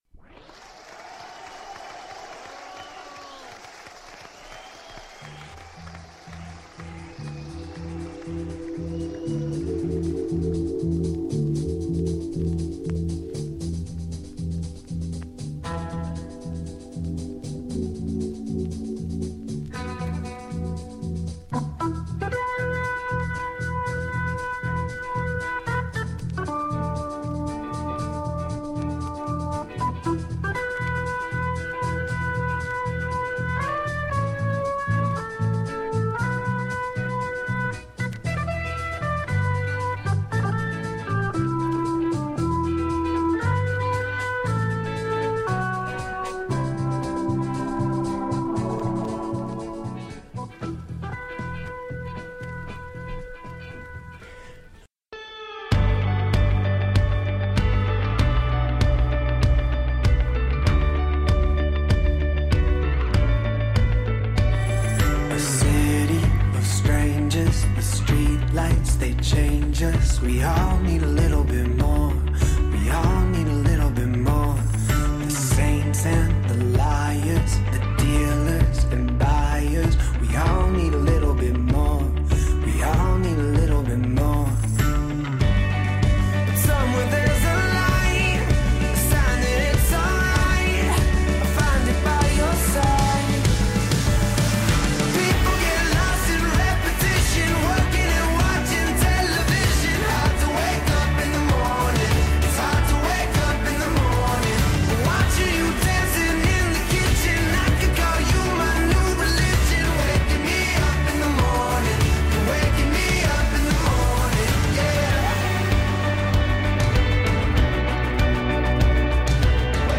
An interview with artist